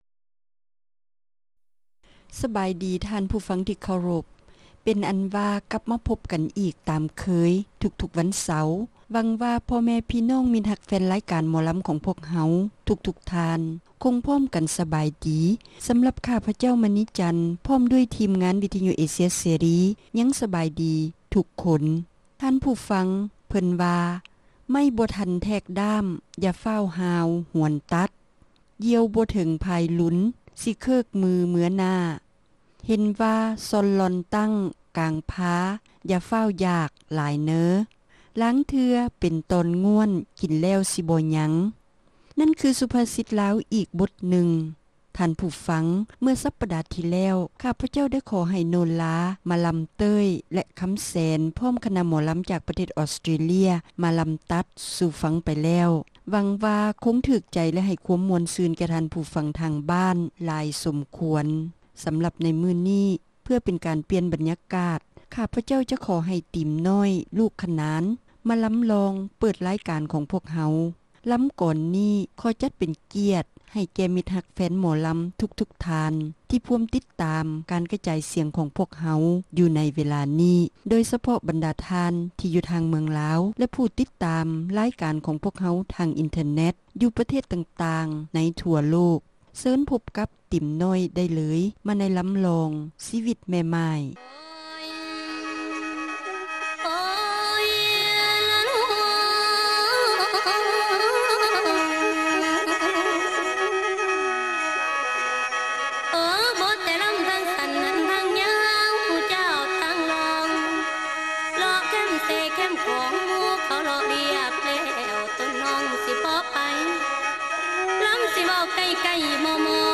ຣາຍການໜໍລຳ ປະຈຳສັປະດາ